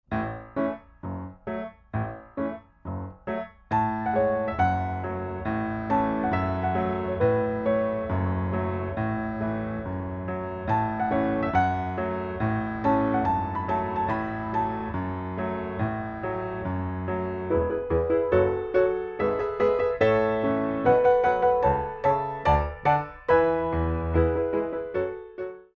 4/4 - 64 with repeat
4 Count introduction included for all selections